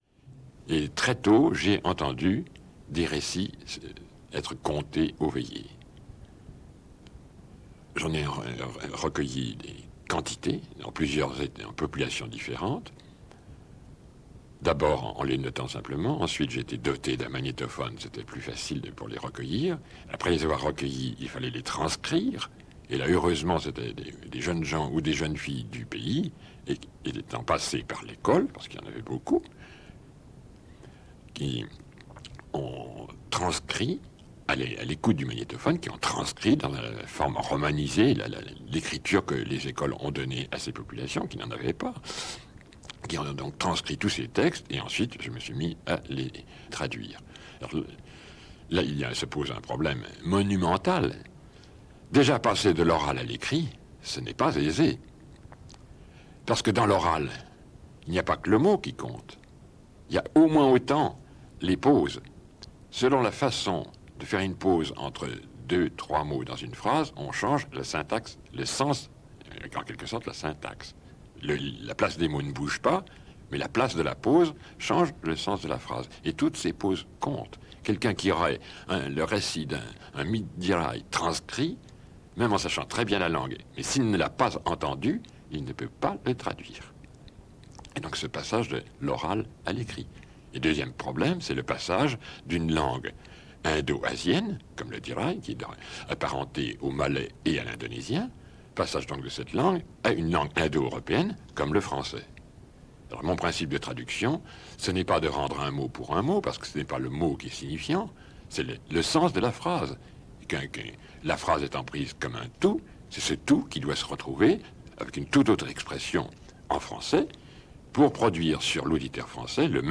Témoignage oral